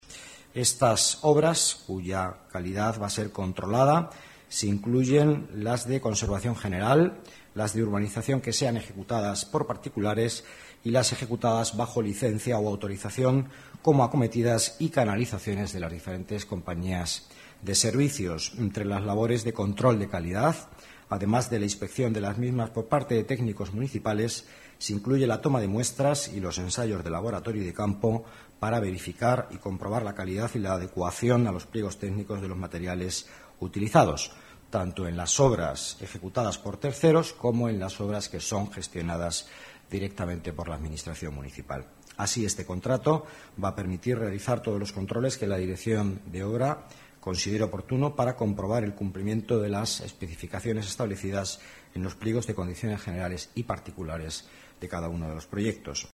Nueva ventana:Villanueva en rueda de prensa, calidad de obras públicas